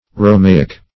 Romaic \Ro*ma"ic\, a. [NGr.